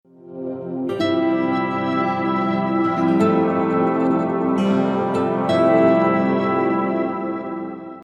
Sound Buttons: Sound Buttons View : Ps4 Startup
ps4-startup.mp3